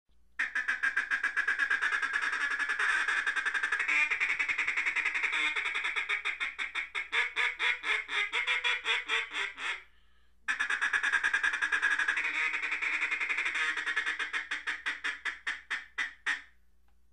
Duck Feed Call The Feeding Call is used after the Greeting call when the ducks are really close and looking to land. It is the sound ducks make when eating with a quack in there sometimes.